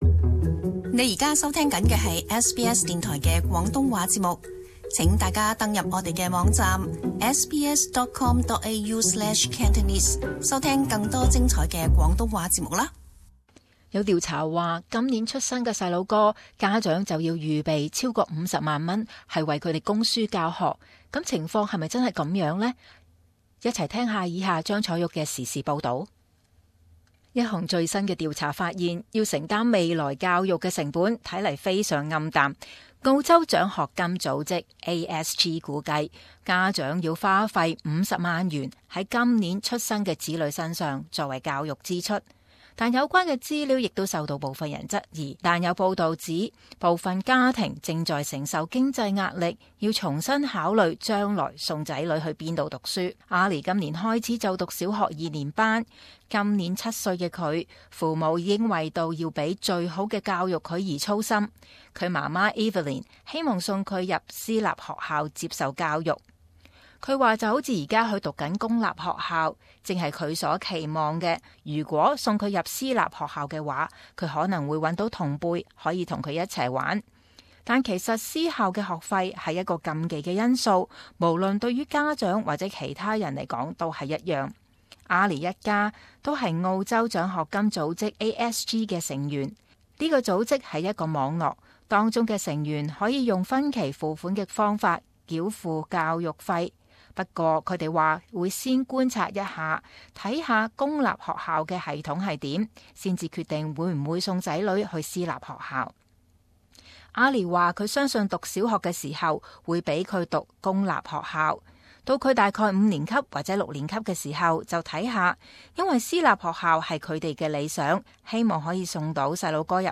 【時事報導】子女教育費要五十萬？